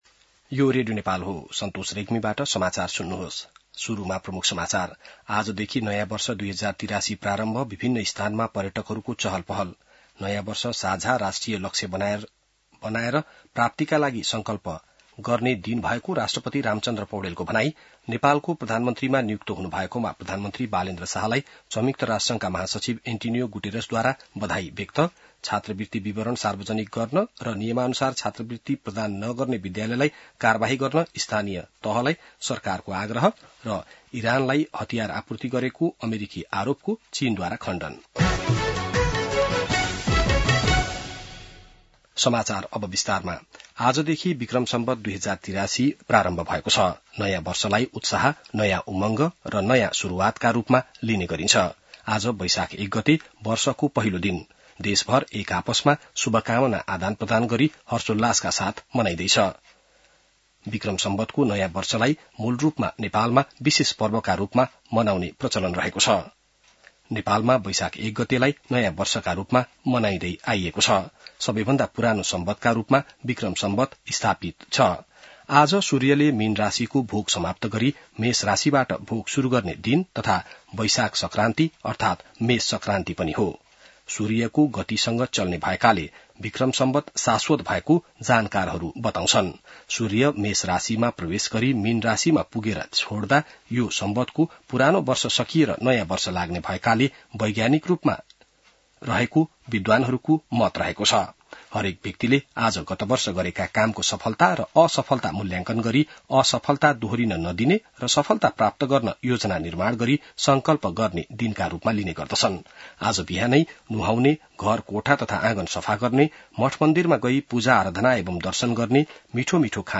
बिहान ९ बजेको नेपाली समाचार : १ वैशाख , २०८३